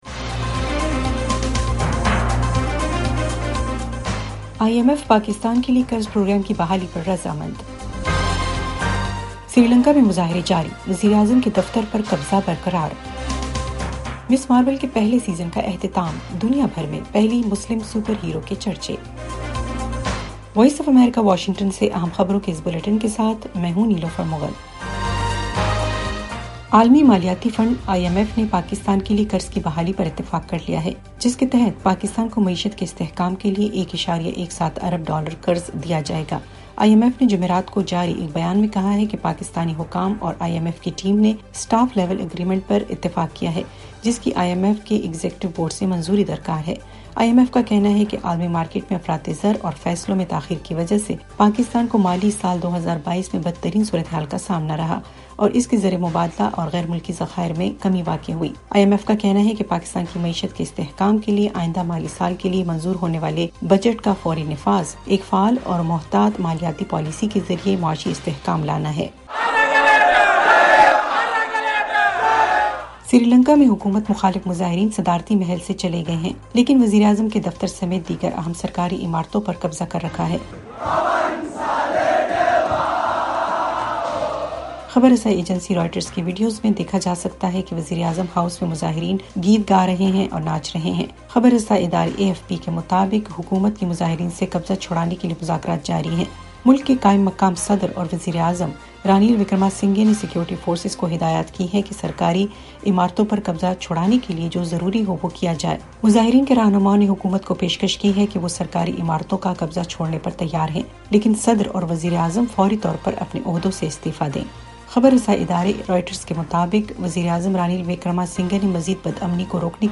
ایف ایم ریڈیو نیوز بلیٹن : شام 6 بجے